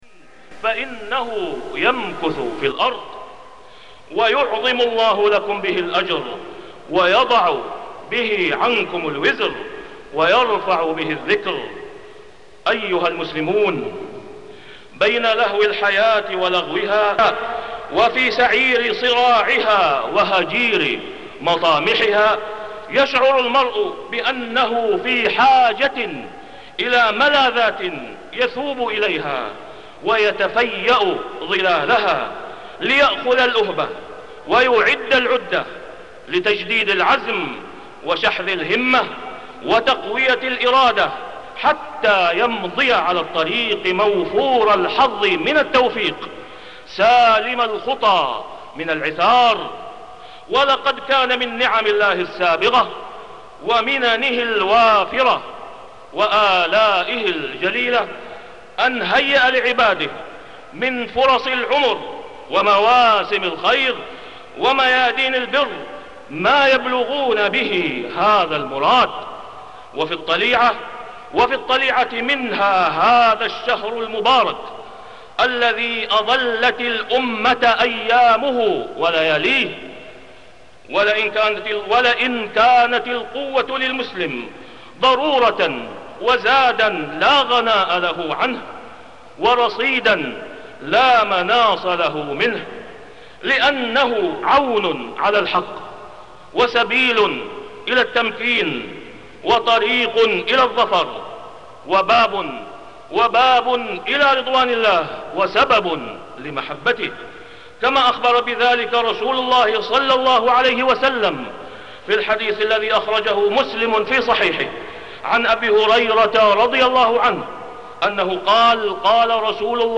تاريخ النشر ٤ رمضان ١٤٢٦ هـ المكان: المسجد الحرام الشيخ: فضيلة الشيخ د. أسامة بن عبدالله خياط فضيلة الشيخ د. أسامة بن عبدالله خياط من خزائن رمضان